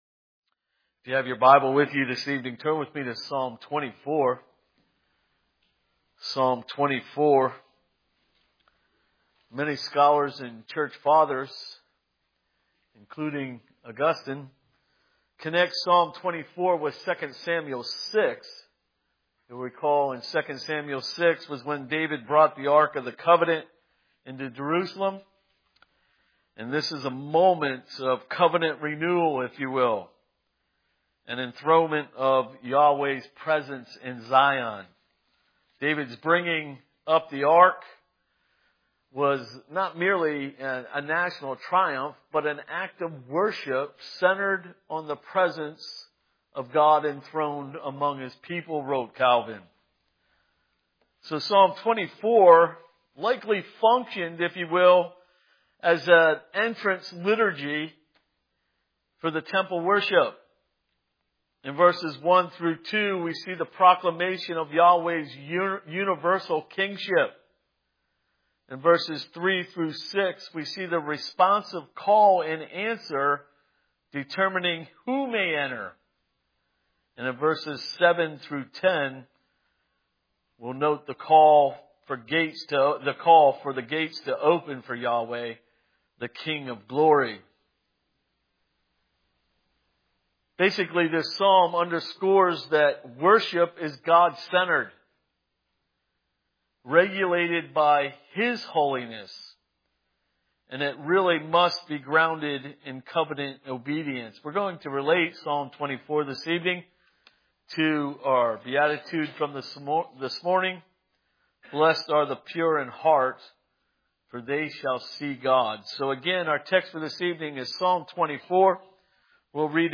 Psalm 24:1-10 Service Type: Sunday Evening Psalm 24:1-10 Who shall ascend the hill of the Lord?